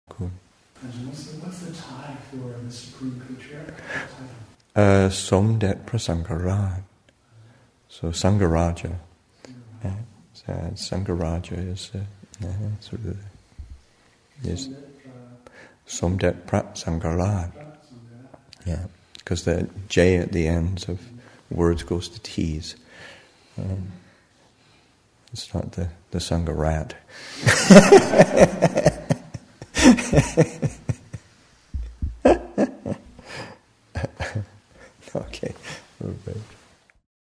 Our Roots in the Thai Forest Tradition, Session 40 – Mar. 2, 2014